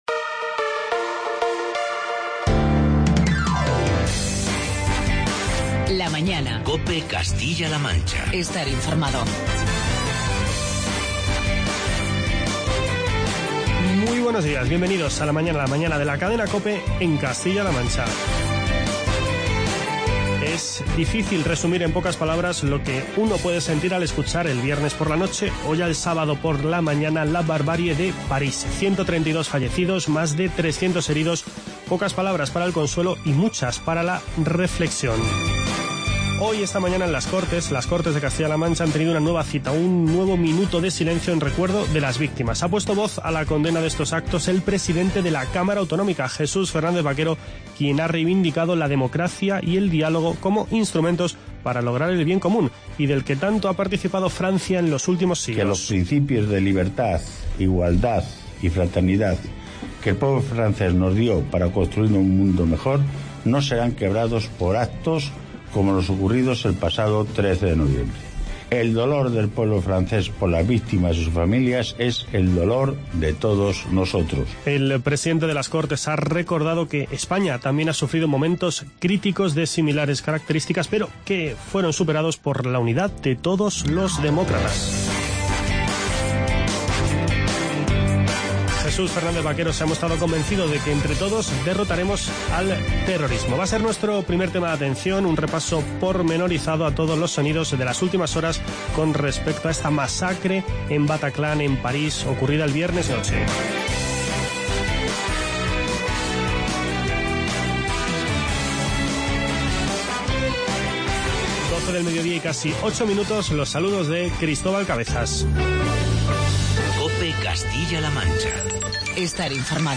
Hacemos un repaso a todos los actos celebrados en Castilla-La Mancha desde que se conoció la terrible noticia de los atentados de París. Además, charlamos de este asunto con el portavoz del Gobierno, Nacho Hernando